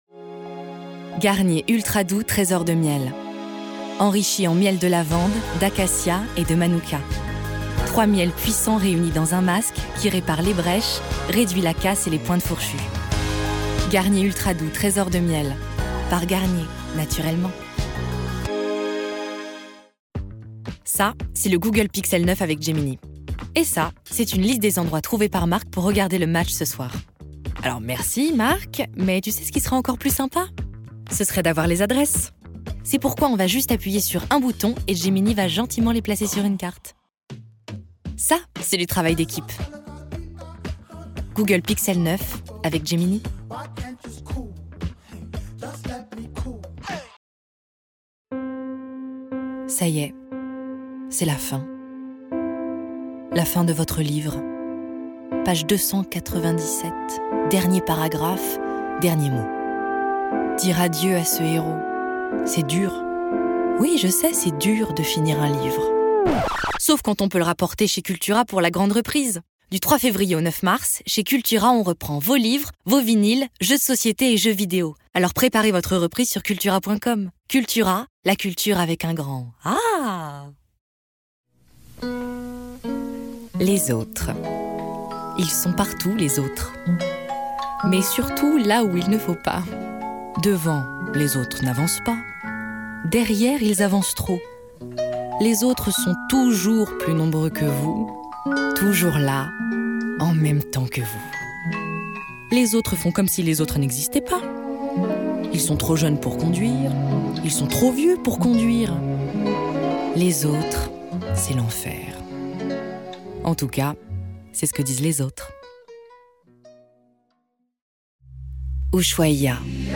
VOIX OFF BANDE DEMO
3 - 100 ans - Mezzo-soprano